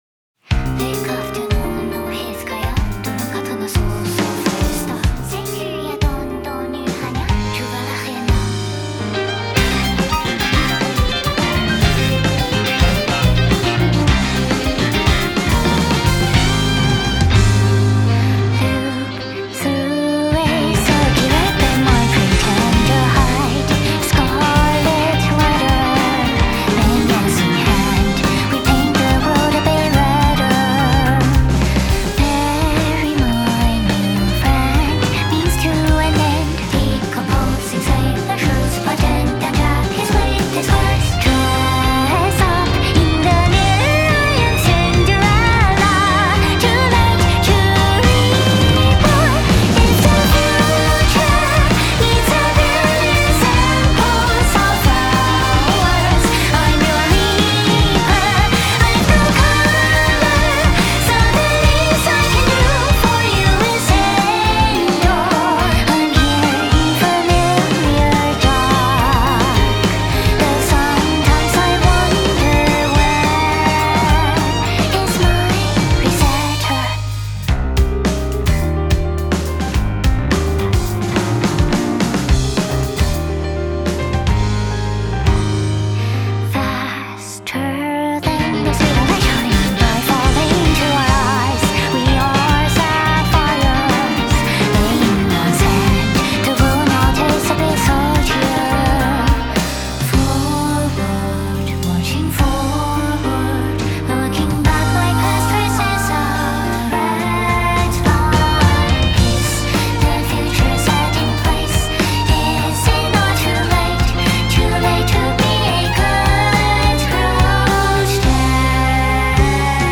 Genre : Anime.